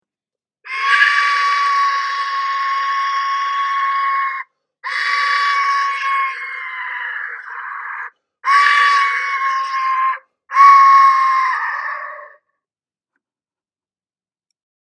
Large Aztec Death Whistle Ancient Mesoamerican Instrument bone white
Click play to hear the sound of the whistle on this page.
This Large Aztec Death Whistle, hand tuned to produce the most frightening scariest sound.
The Large Aztec Death whistle is a hand crafted musical instrument producing the loudest, scariest, terrifying sound around.